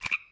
Frog Throw Sound Effect
Download a high-quality frog throw sound effect.
frog-throw.mp3